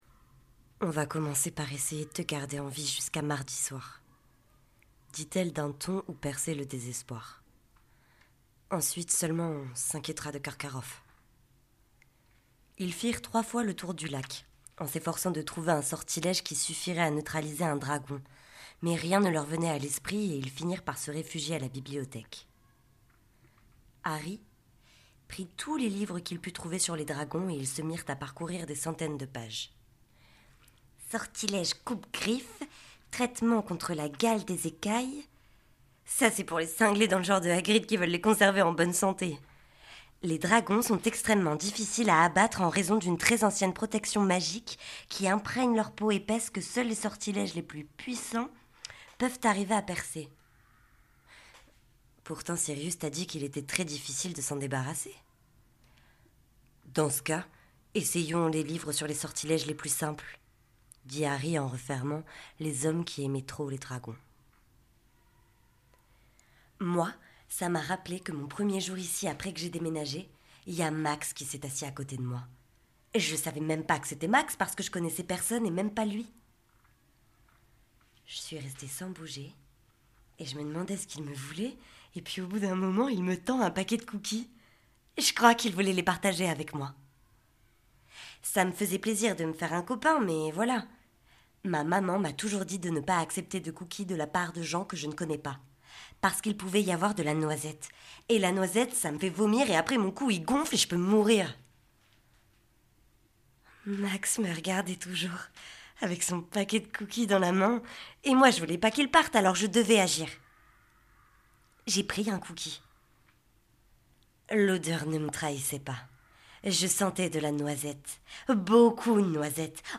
Bande démo livre audio
- Soprano